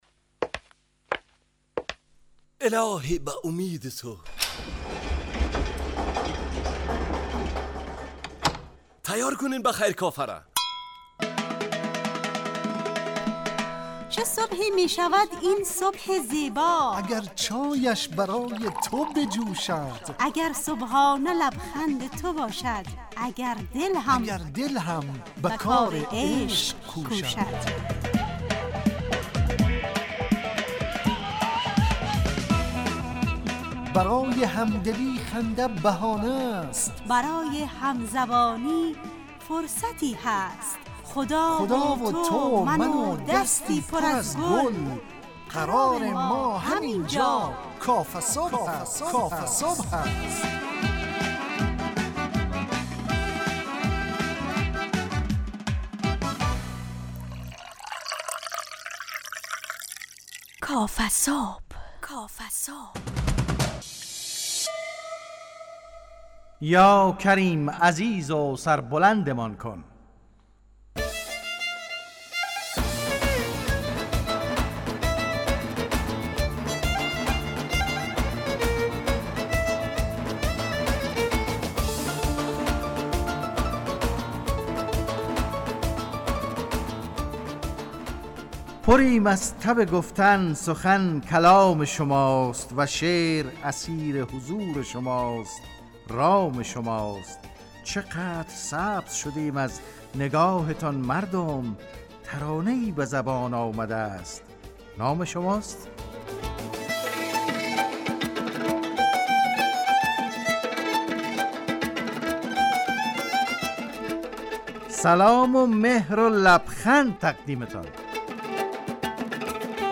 کافه صبح - مجله ی صبحگاهی رادیو دری با هدف ایجاد فضای شاد و پرنشاط صبحگاهی همراه با طرح موضوعات اجتماعی، فرهنگی، اقتصادی جامعه افغانستان همراه با بخش های کارشناسی، نگاهی به سایت ها، گزارش، هواشناسی و صبح جامعه، گپ صبح و صداها و پیام ها شنونده های عزیز